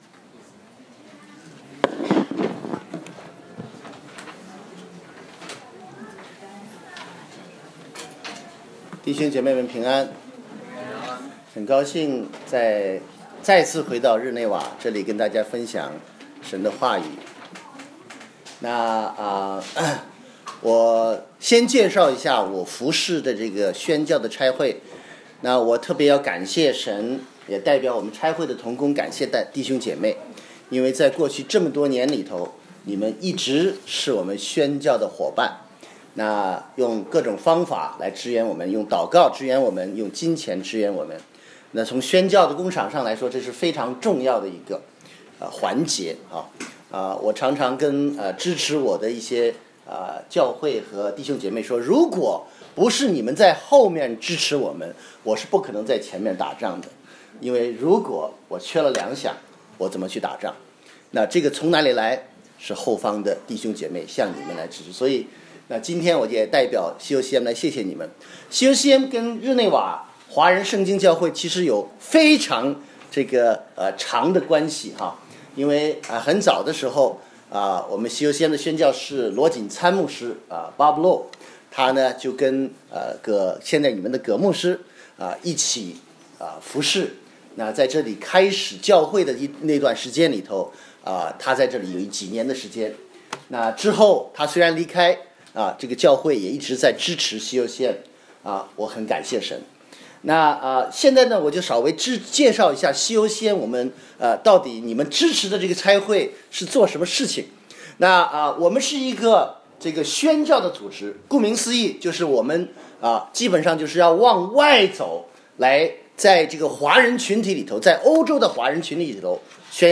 2018年11月18日主日讲道：坐下来